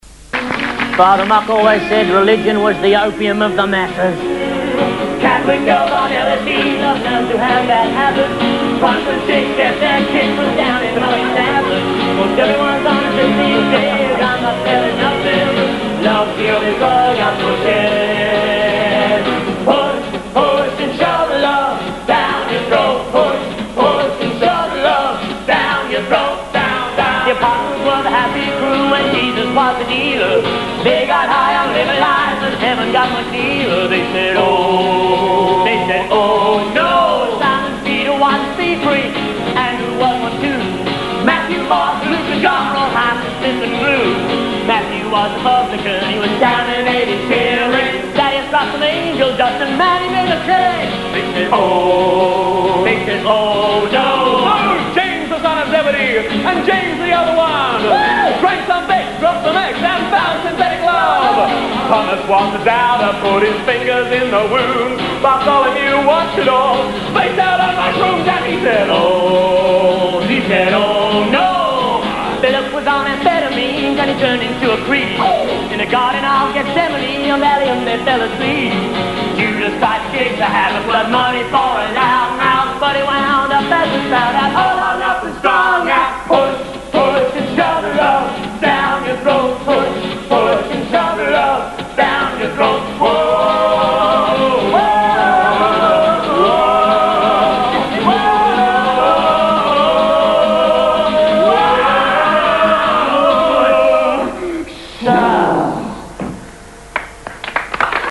backing vocals, turning each song into a dreamy acapella.
guitar